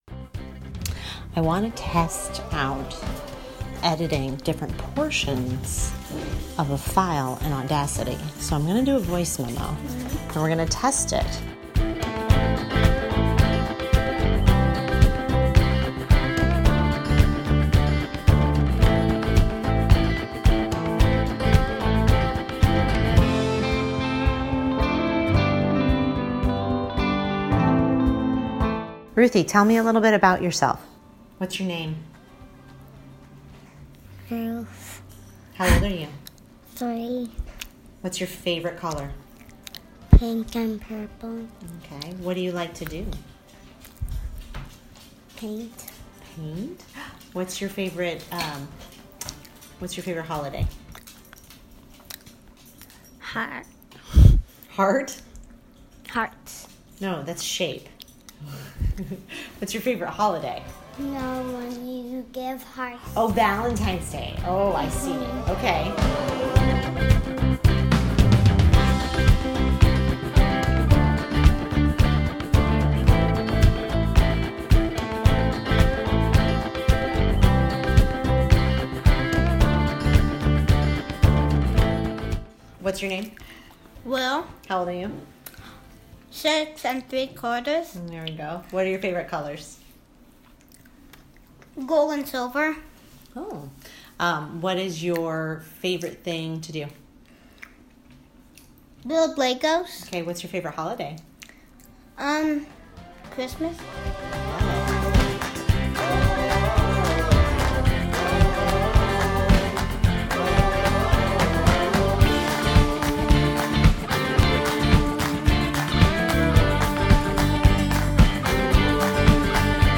I am now testing out a documentary format with my podcast, choosing to attempt a more polished (READ: more editing) style for each episode. This means that instead of just a few cuts and trims I am actually layering audio, selecting sounds bites and writing and recording voice over tracks, learning to edit tracks on top of one another.